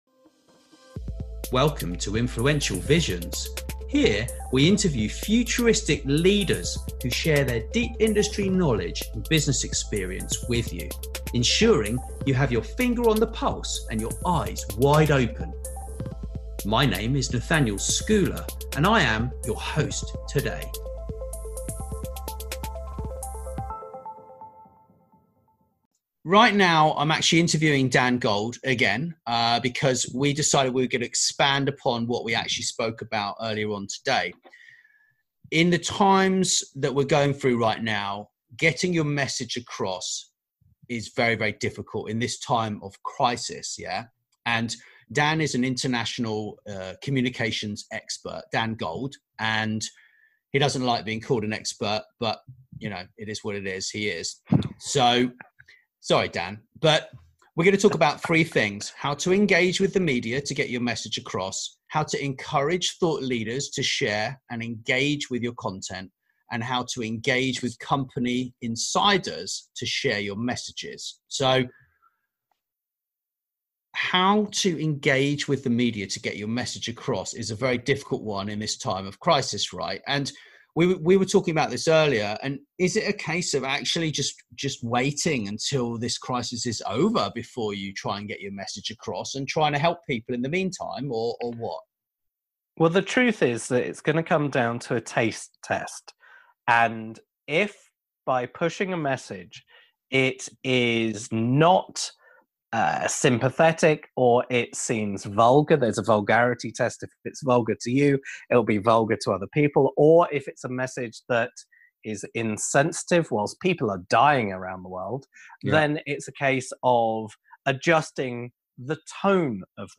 Getting your message across in times of crisis: PR and Comms Expert Talk